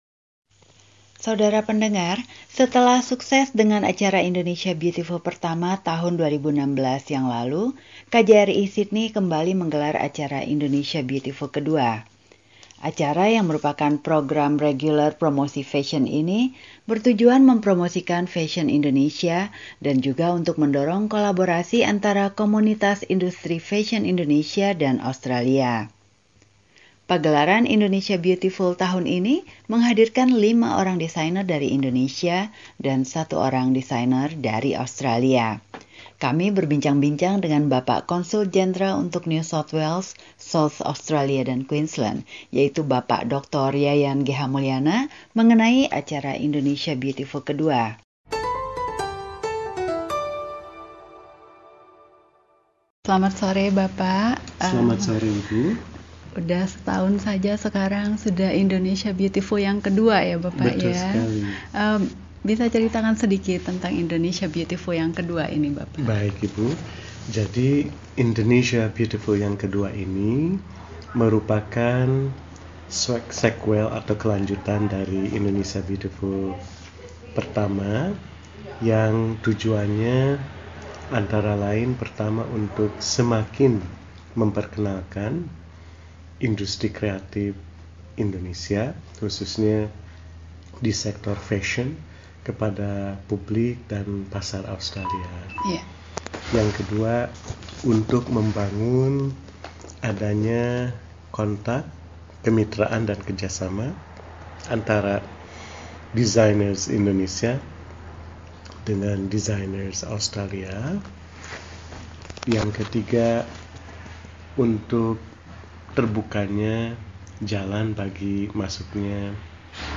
Wawancara dengan Konsul Jenderal Indonesia untuk New South Wales, Queensland, dan South Australia, Bapak Yayan G.H. Mulyana tentang gelaran acara Indonesia Beautiful ke-2 .